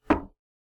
default_stone_2.ogg